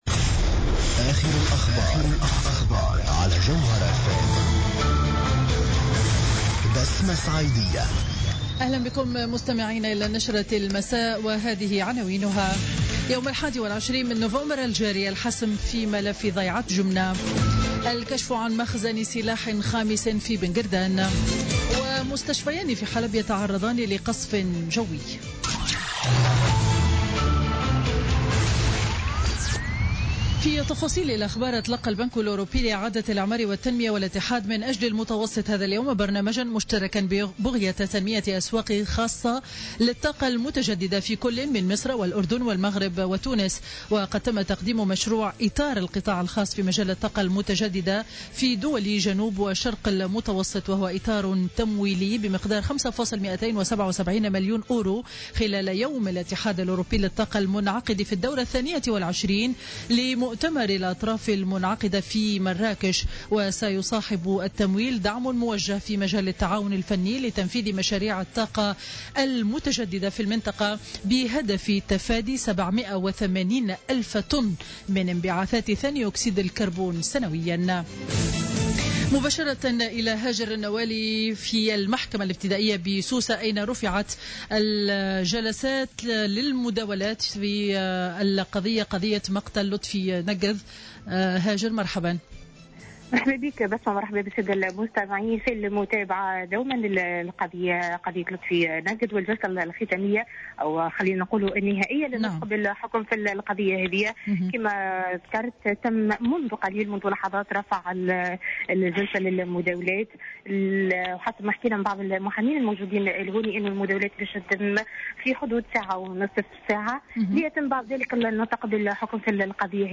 نشرة أخبار السابعة مساء ليوم الاثنين 14 نوفمبر 2016